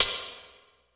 59 O HH 1 -L.wav